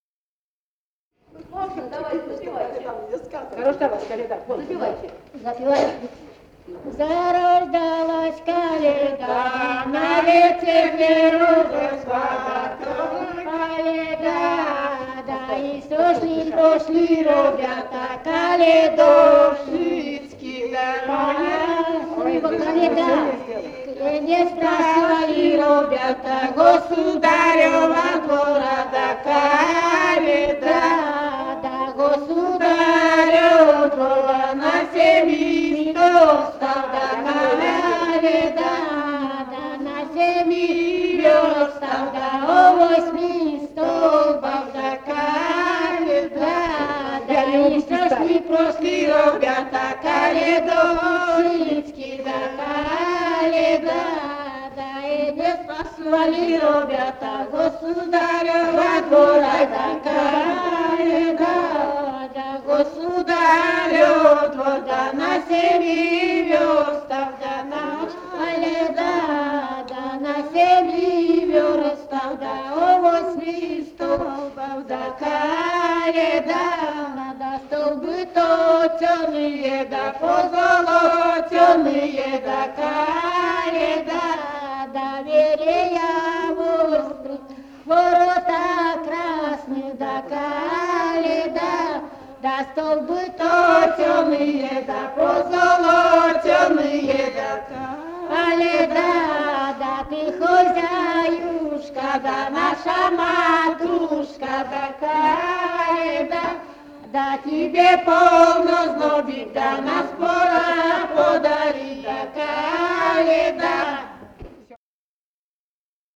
«Зарождалась Коляда» (колядка).